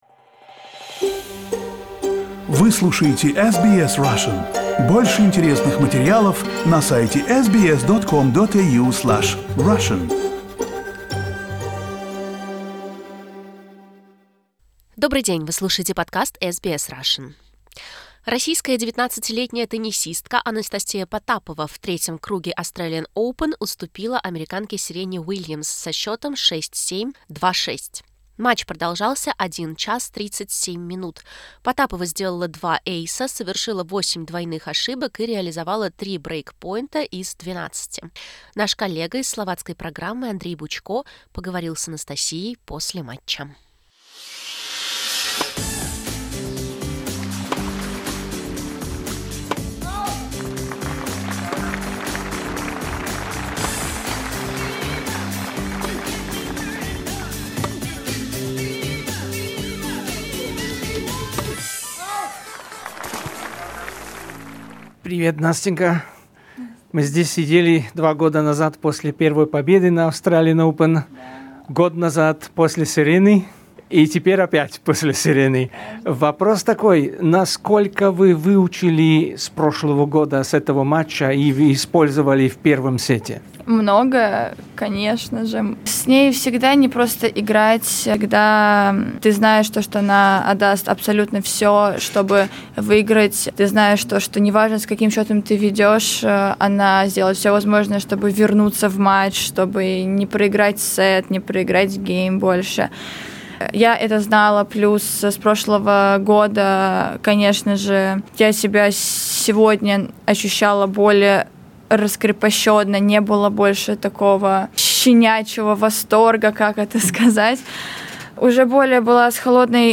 Exclusive interview in Russian for SBS Russian.